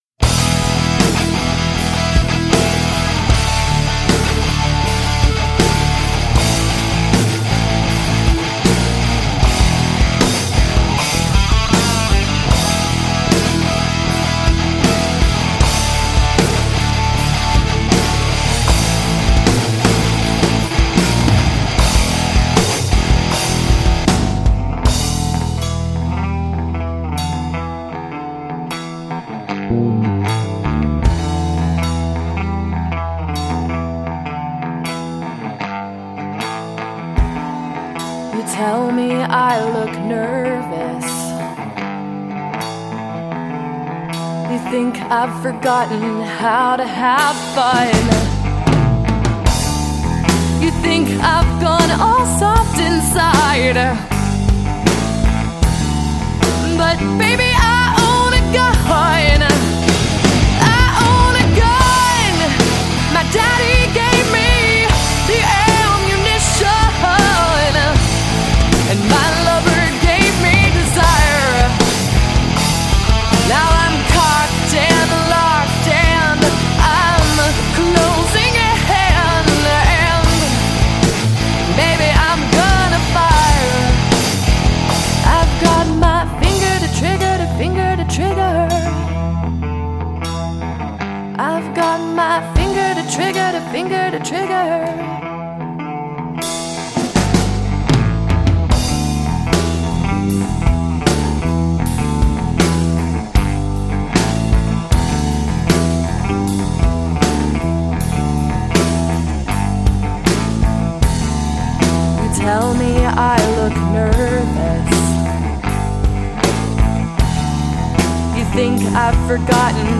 female-fronted hard rock band